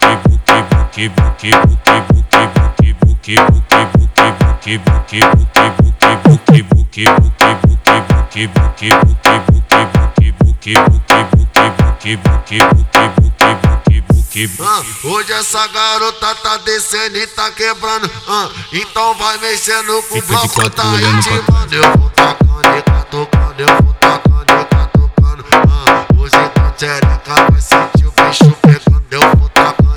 Baile Funk
Жанр: R&B / Соул / Фанк